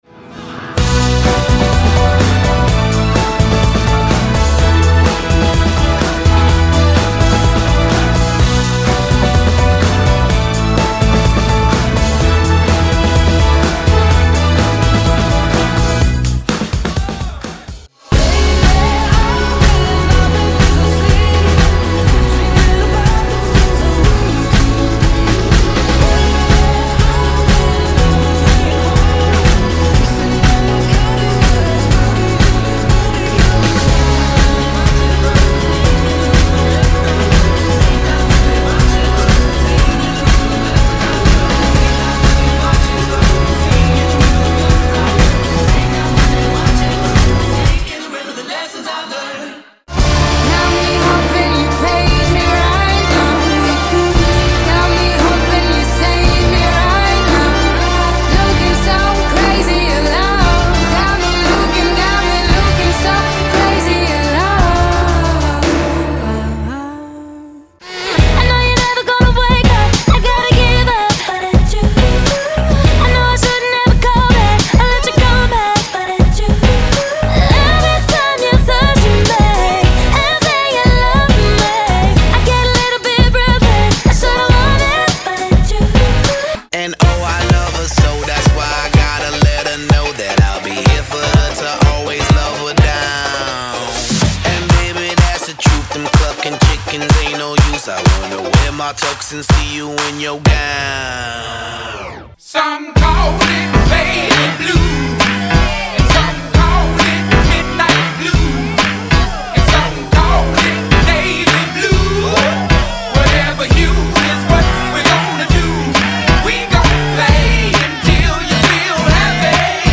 плагин помог уложить абсолютно расношёрстный материал по стилям и характеру сведения в один звук.и уже в демке дал заработать на его покупку....)))